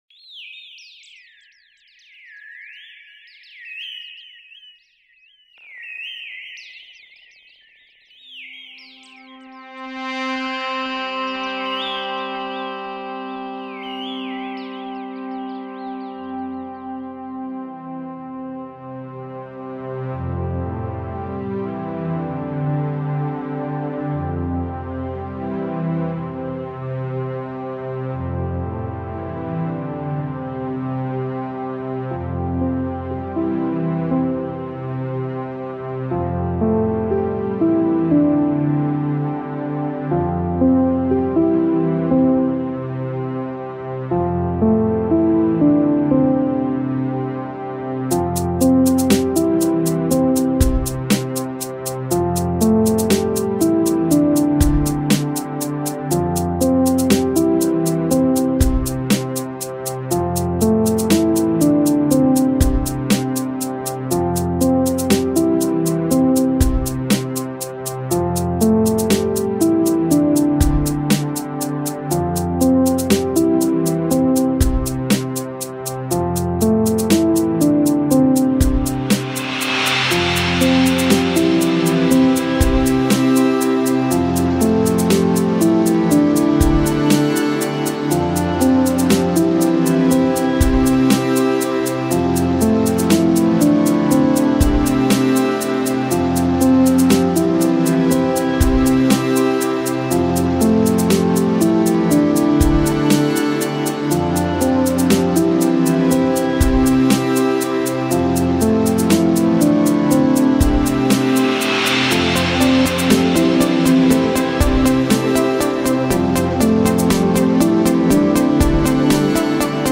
Here is something for you all to relax to.